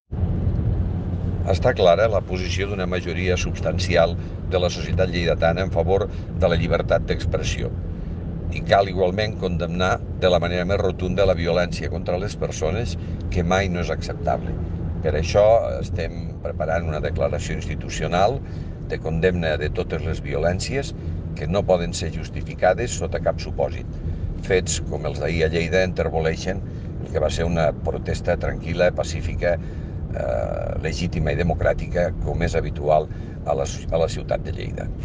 Tall de veu de Miquel Pueyo Fruit dels aldarulls d’ahir al vespre van resultat ferits lleus sis agents entre Mossos d’Esquadra i Guàrdia Urbana.
tall-de-veu-de-lalcalde-miquel-pueyo